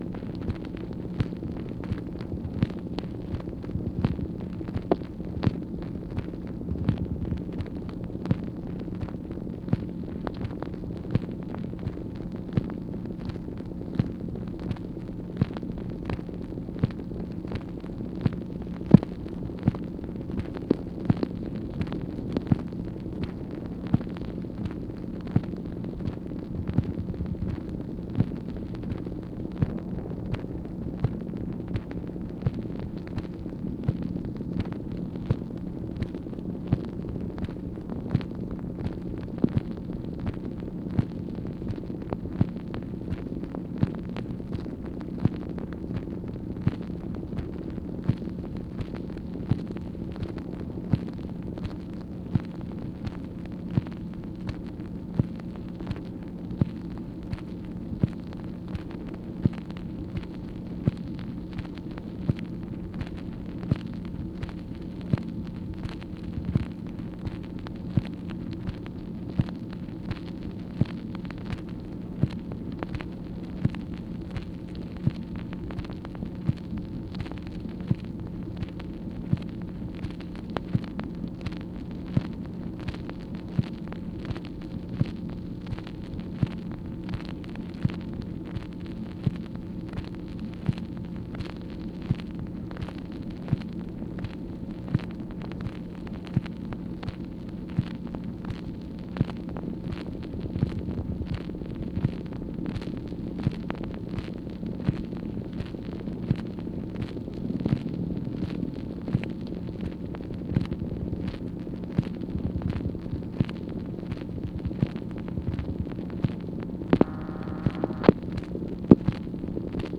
MACHINE NOISE, March 3, 1964
Secret White House Tapes | Lyndon B. Johnson Presidency